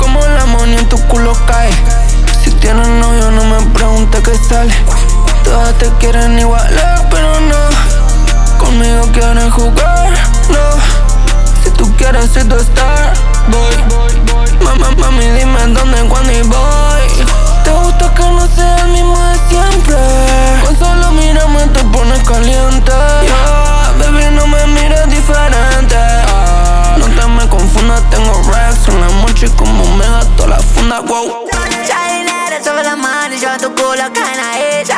Latin